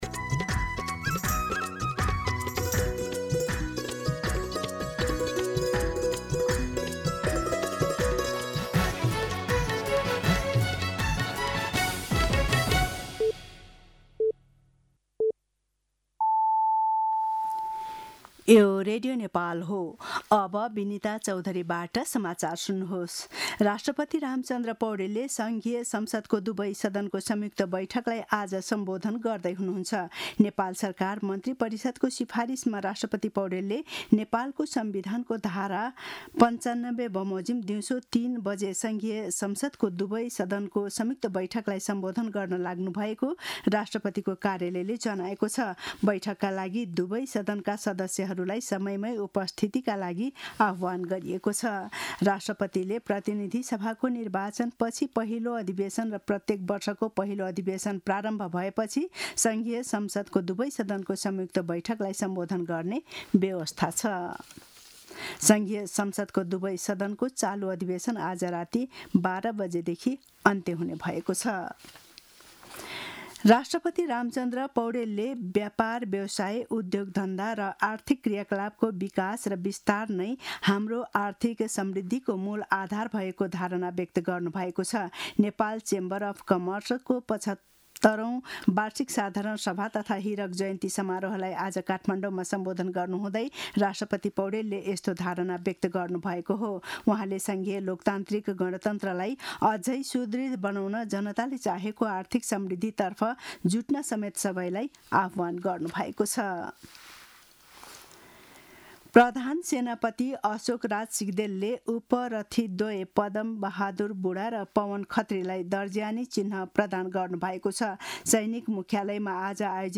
दिउँसो १ बजेको नेपाली समाचार : २७ चैत , २०८२
1-pm-News-12-27.mp3